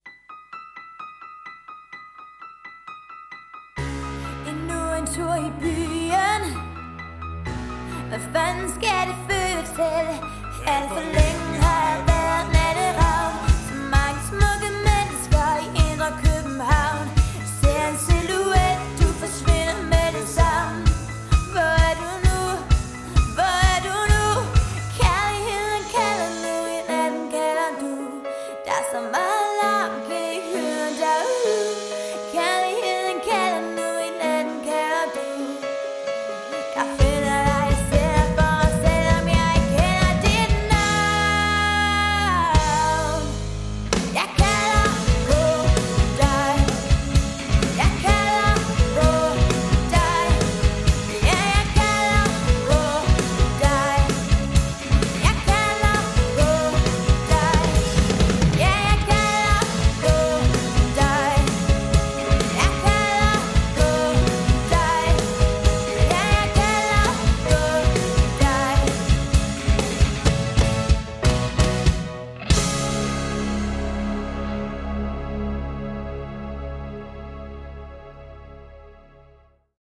leverer dem som rock-sange